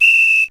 whisle.mp3